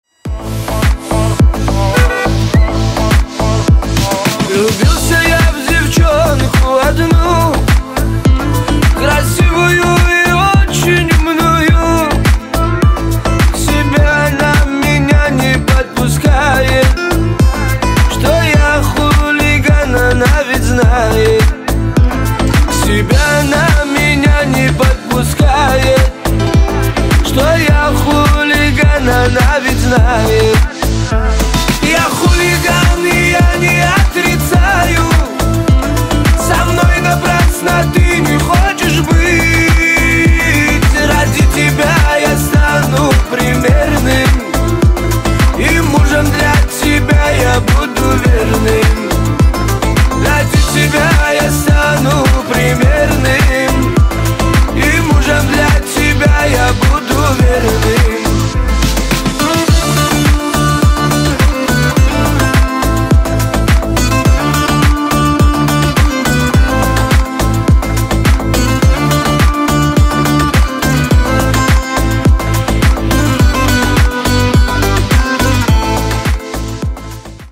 • Качество: 320, Stereo
поп
мужской вокал
кавказские